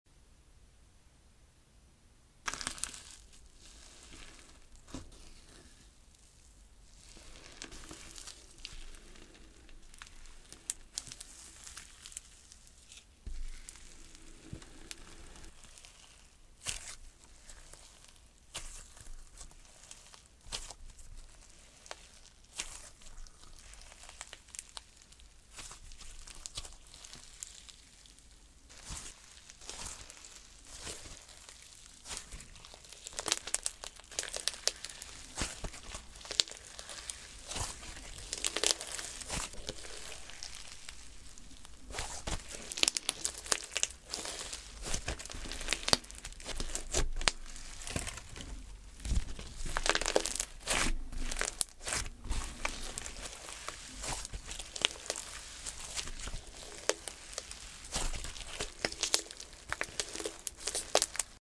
Cloudfizz slime ASMR review 🛹 sound effects free download